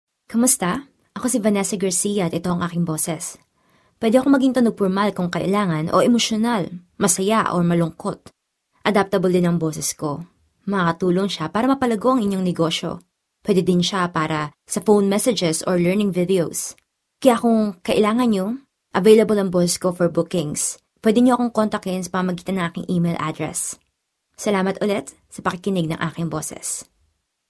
Filipince Seslendirme
Kadın Ses